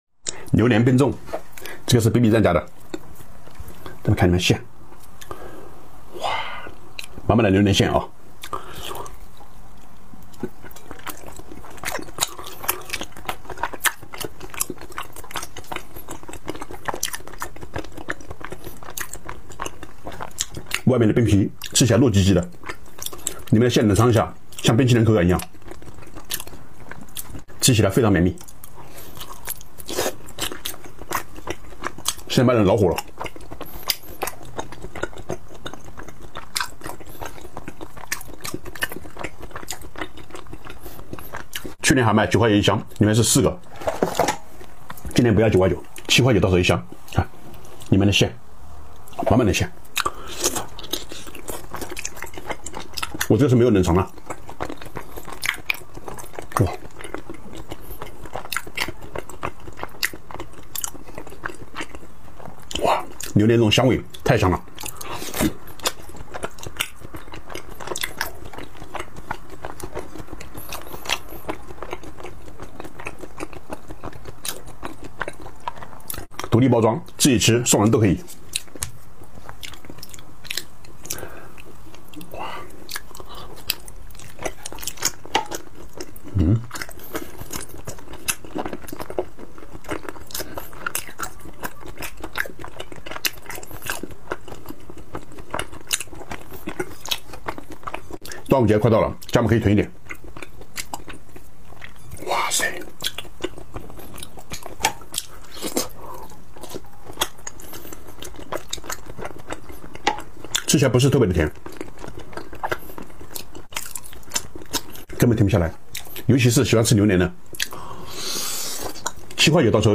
Upload By ASMR EATING